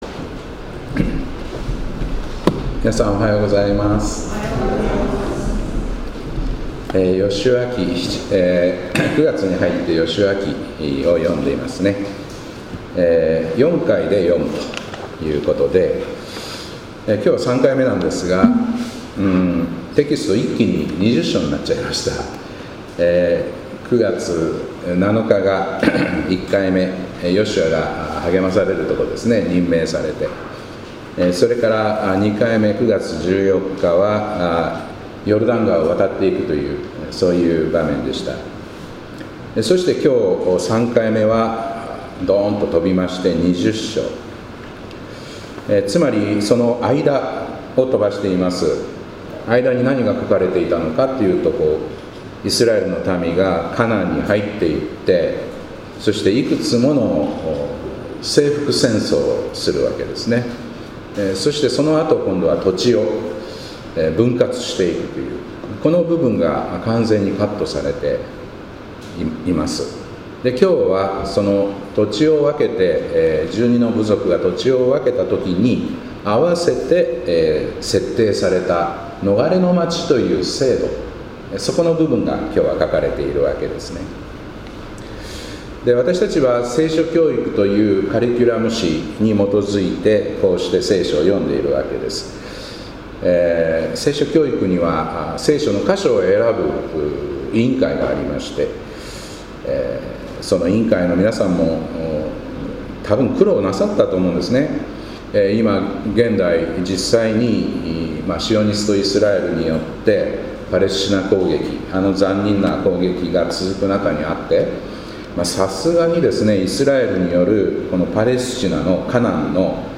2025年9月21日礼拝「復讐という正義」からの解放